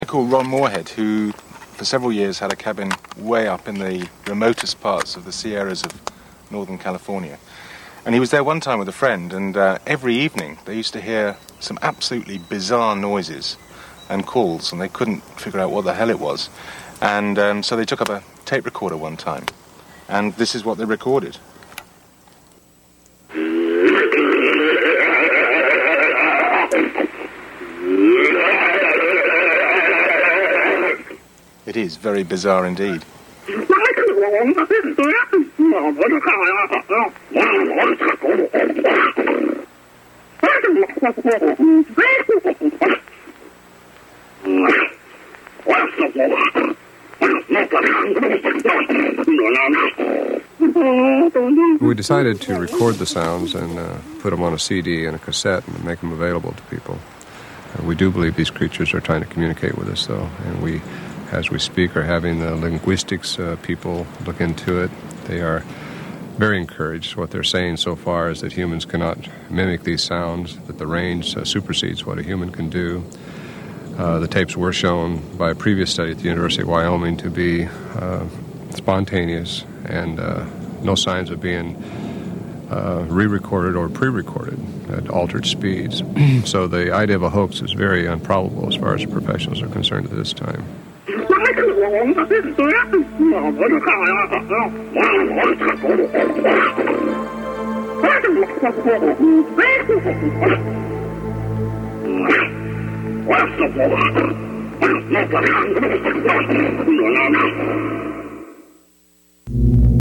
There are many sound recordings that are apparently emminating form a large mammal with tremedous vocal capabilities, both in range and volume.
The most intriguing sound that has been reported and recorded is a gibbering, babbaling type of comunication that resembles a very primitve form of language.
Sierra Recording with narration